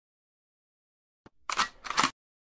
مؤثر صوت تعمير سلاح اهو Sound Effects Free Download